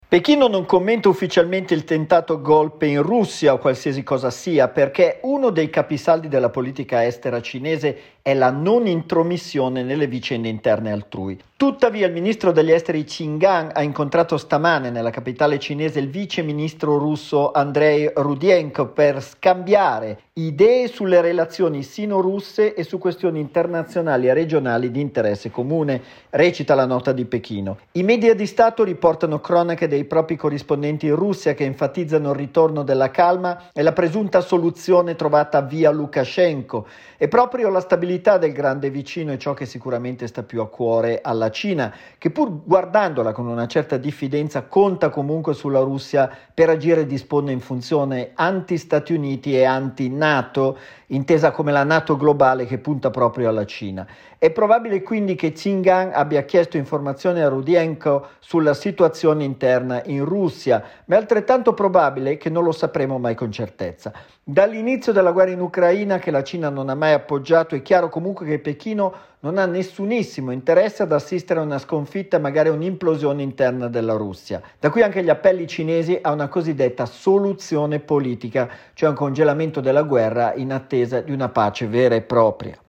Intanto il viceministro degli esteri russo vola a Pechino per confermare il sostegno cinese alla leadership di Putin. Il servizio